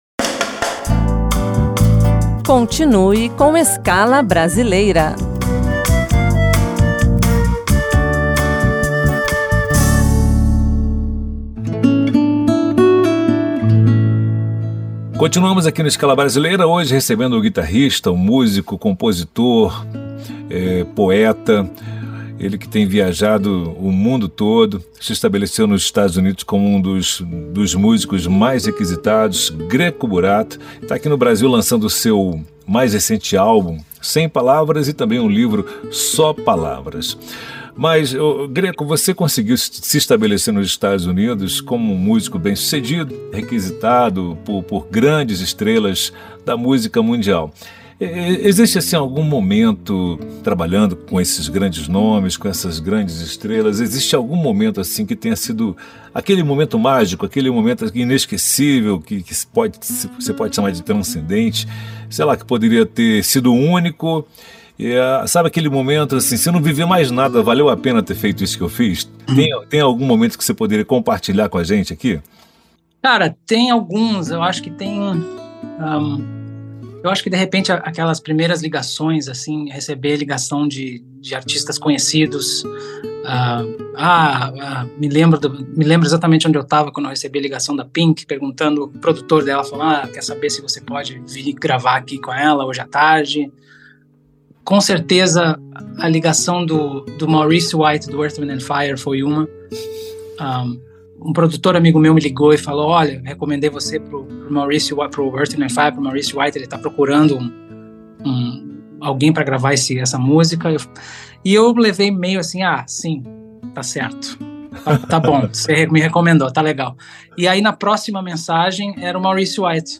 Nesta entrevista